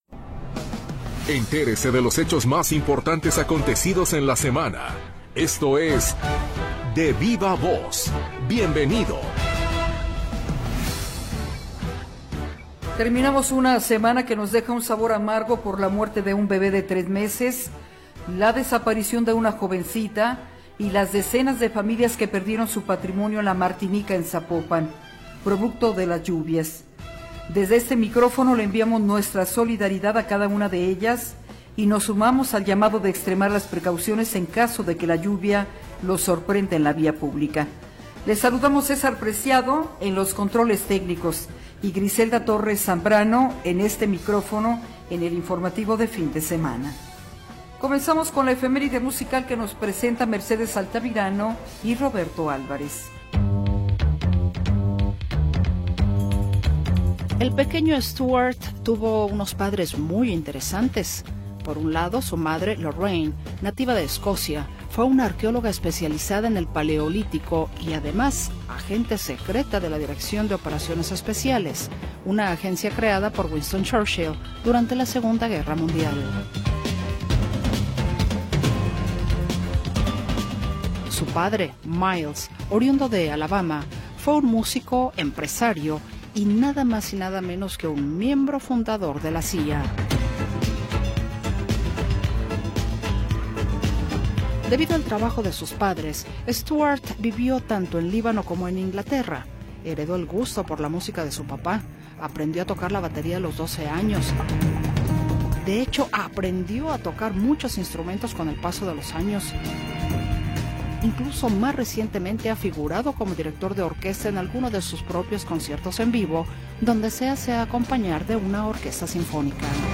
Lo mejor de las entrevistas de la semana en Radio Metrópoli.